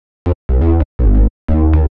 TSNRG2 Bassline 014.wav